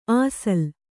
♪ āsal